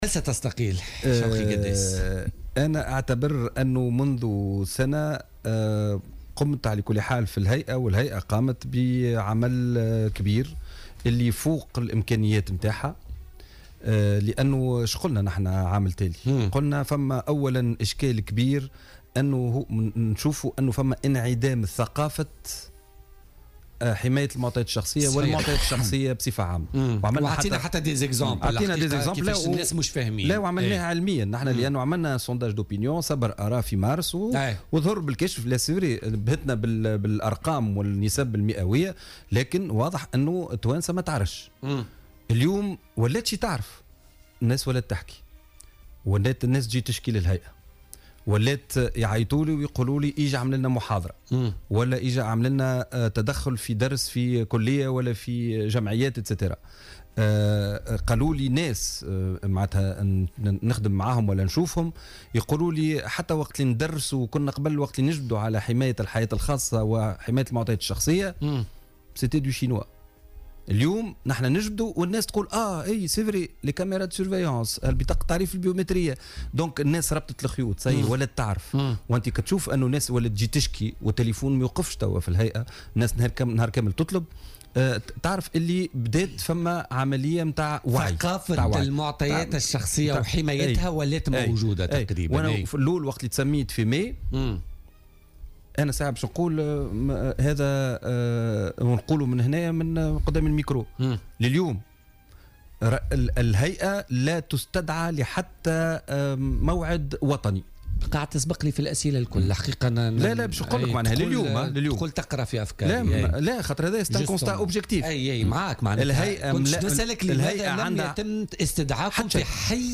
وأضاف قداس، ضيف برنامج "بوليتيكا" اليوم الخميس أن الهيئة قامت بعمل كبير يفوق إمكانياتها، مشيرا إلى تنامي الوعي بأهمية دورها و انتشار ثقافة حماية المعطيات الشخصية لدى الشعب التونسي، حيث أكد أنها أصبحت تتلقى يوميا عددا كبيرا من التشكيات.